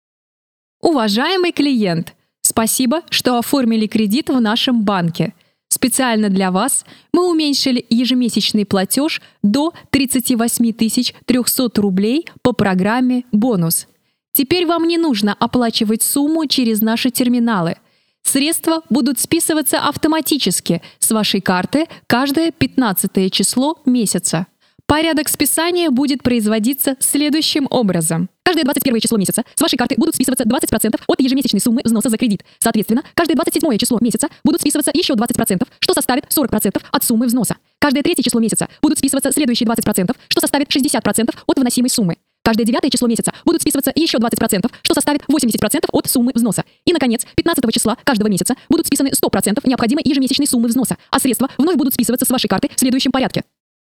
Самый громкий звук звонка